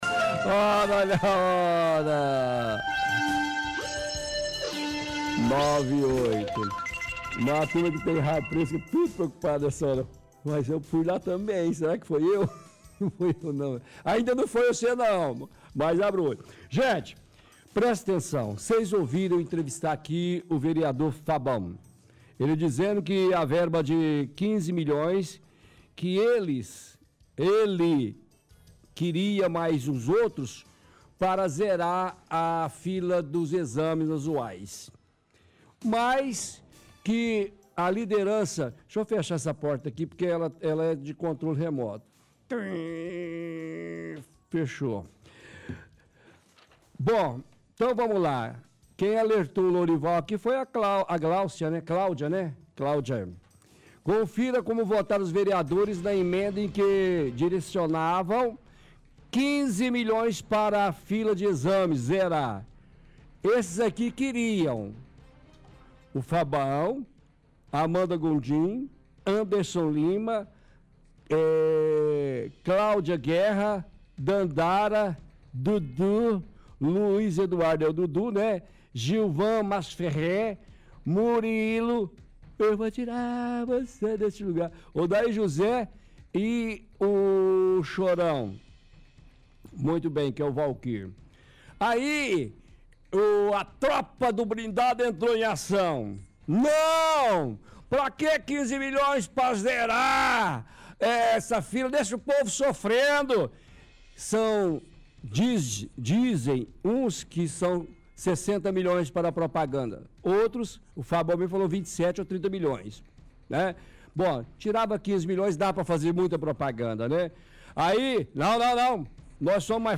– Faz deboches e críticas enquanto fala dos vereadores.